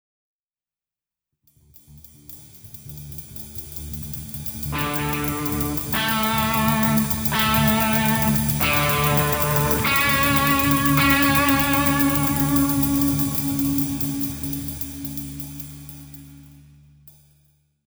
As shown in FIGURE 2, pick the D note, then sing the note a fifth above it, which is A. Then play the A note at the seventh fret on the fourth string to verify if you have sung this pitch accurately.